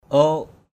/o:ʔ/ (t.) đực = male. kabaw ok kb| oK trâu đực = buffle male; manuk ok mn~K oK gà trống = coq.